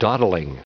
Prononciation du mot dawdling en anglais (fichier audio)
Prononciation du mot : dawdling